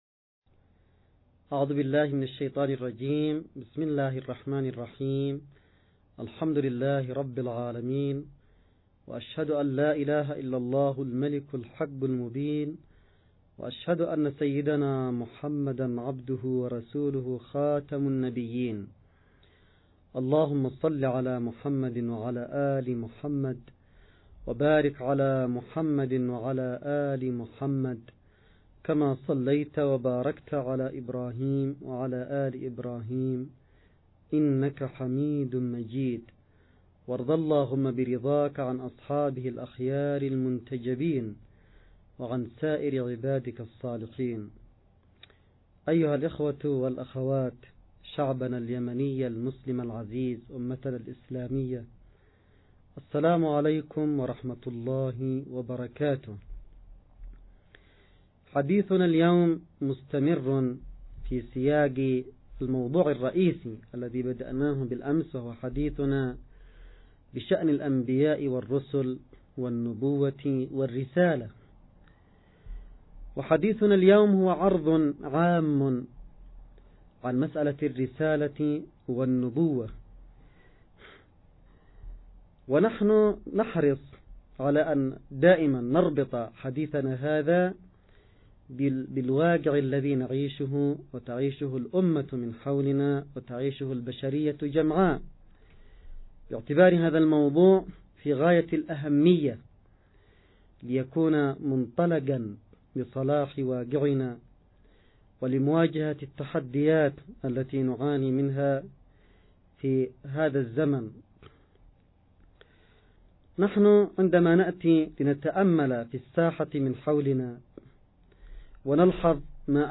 نص + فيديو + أستماع لمحاضرة السيد عبدالملك بدرالدين الحوثي – المولد النبوي ـ 8 ربيع اول 1439هـ – المحاضرة الثانية.
المحاضرة_الثانية_للسيد_عبدالملك.mp3